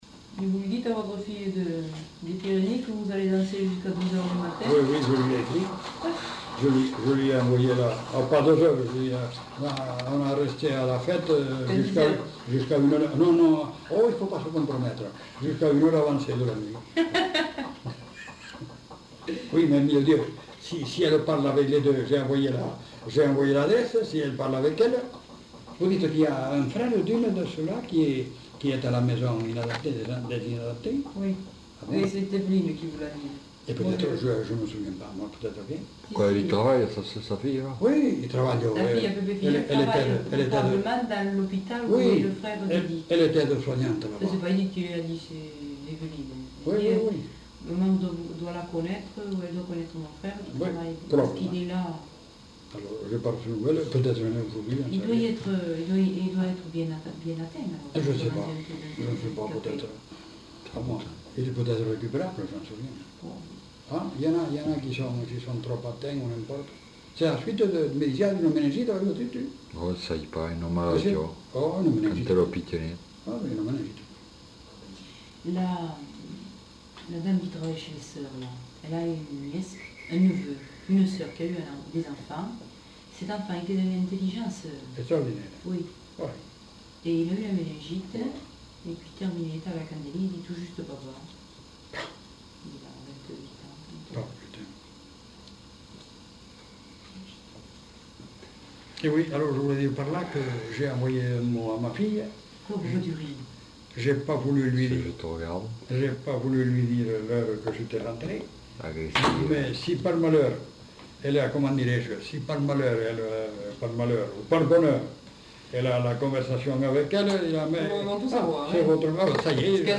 Discussion de fin d'enquête
Aire culturelle : Haut-Agenais
Genre : parole